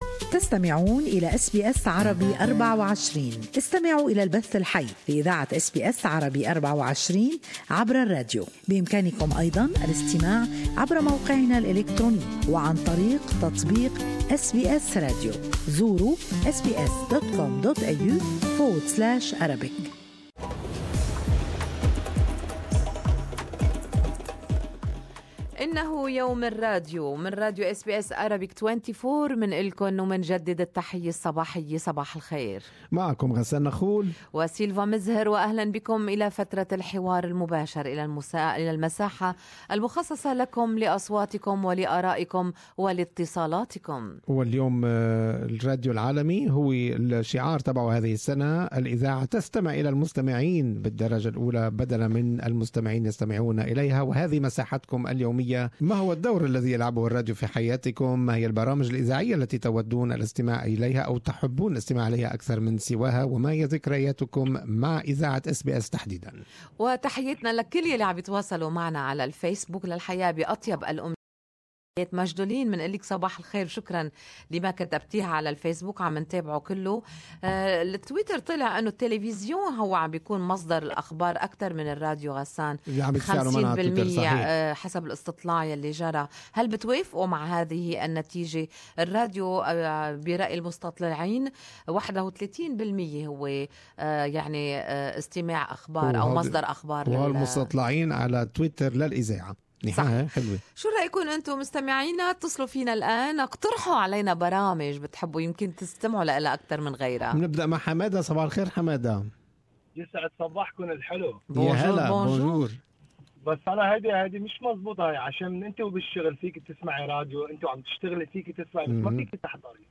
Our beloved listeners on the Good Morning Australia show shared their experiences with radio. They spoke of the bond they share with SBS and the their loyalty to this channel and program.